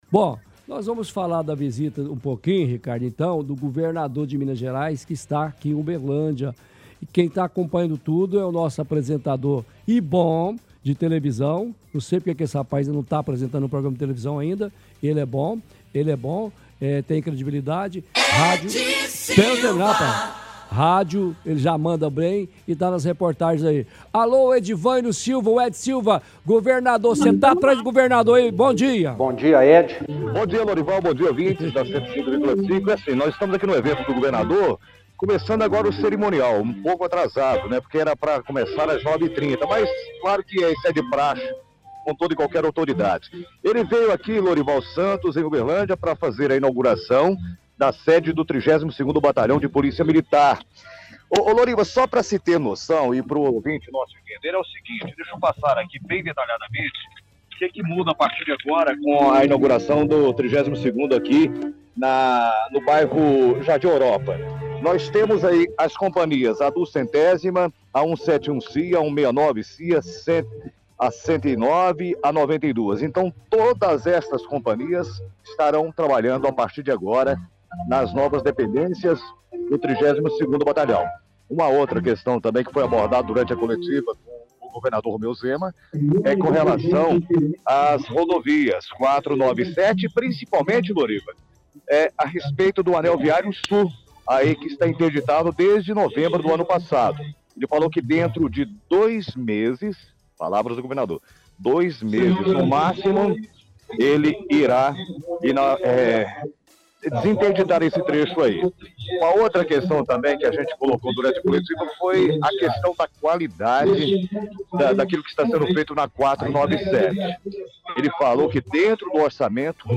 fala ao vivo de evento com presença do governador Romeu Zema fazendo inauguração de batalhão da polícia militar.